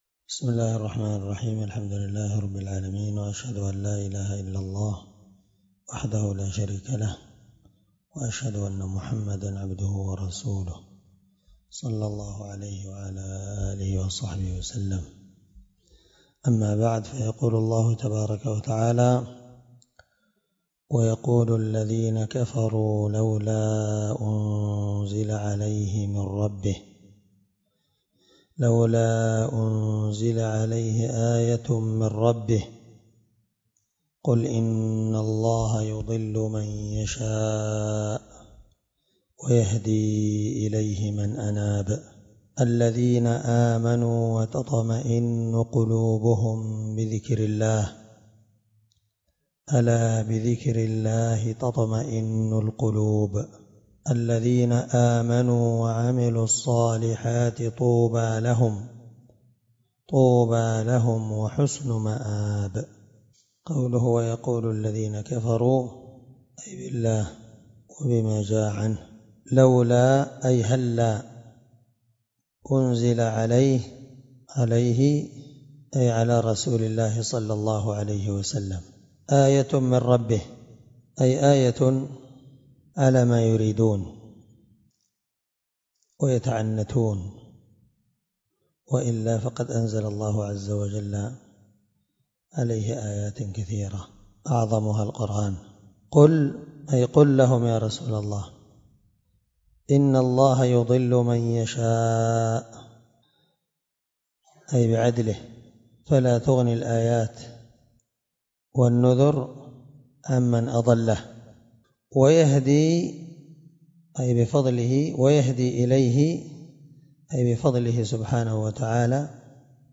687تفسير السعدي الدرس11 آية (27-29) من سورة الرعد من تفسير القرآن الكريم مع قراءة لتفسير السعدي